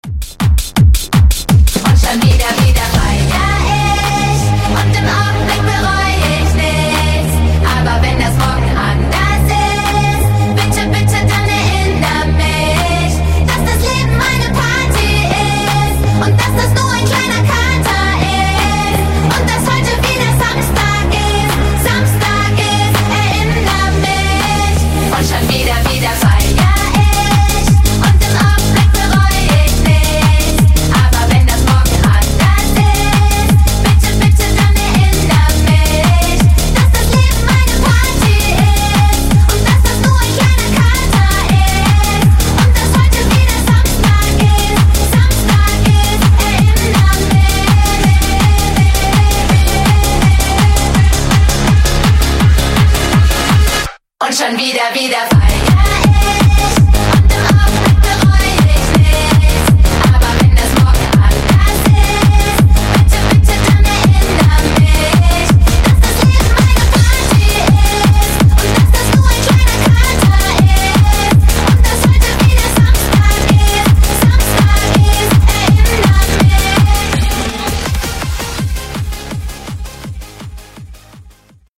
Genre: AFROBEAT
Dirty BPM: 105 Time